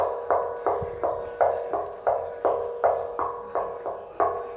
描述：扔球
Tag: 抛出 木材 篮球